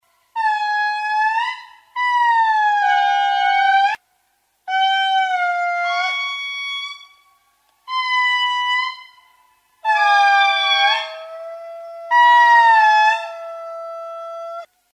Звуки лемуров
Вой лемуров